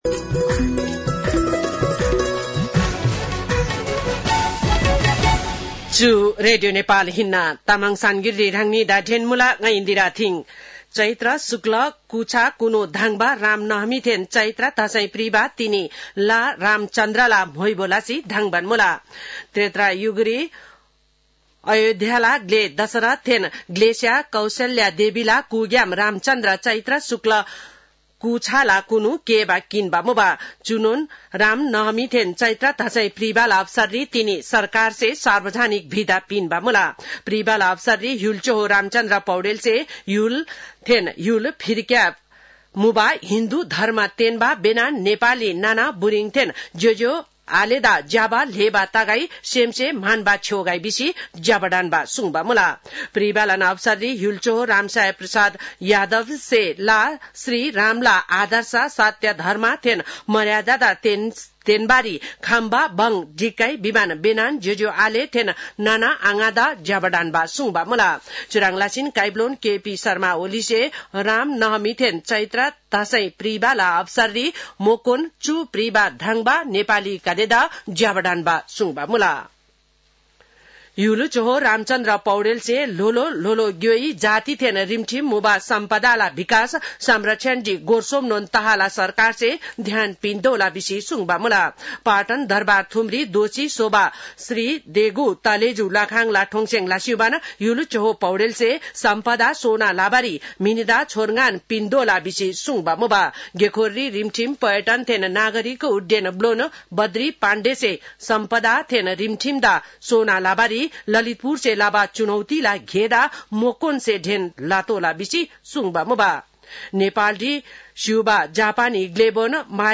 तामाङ भाषाको समाचार : २४ चैत , २०८१